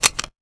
weapon_foley_pickup_06.wav